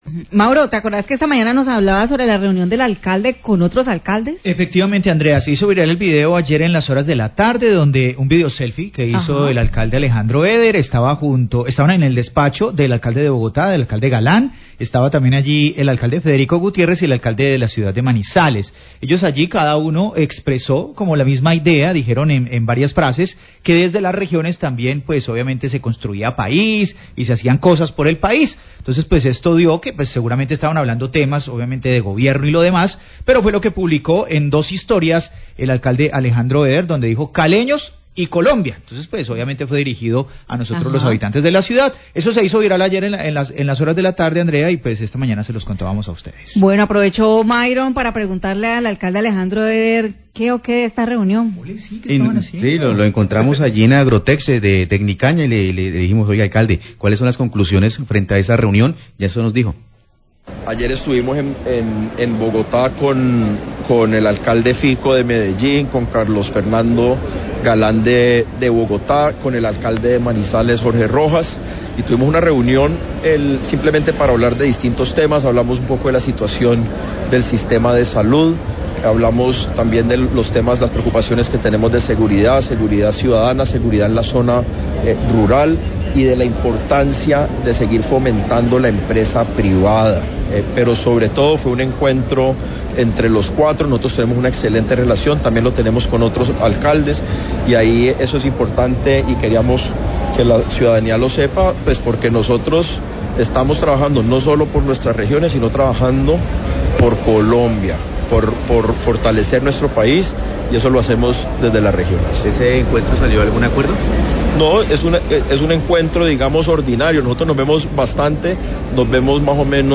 Alcalde Eder habla de reunión en Bogotá con otros 3 alcaldes del país
El Alcalde de Cali, Alejandro Eder, habló de las conclusiones de la reunión con sus homólogos de Bogotá, Medellín y Manizalez en al capital del país, donde hablaron de temas neuralgico para las regiones como la situación del sistema de salud, de seguridad y de fomento de la empresa privada.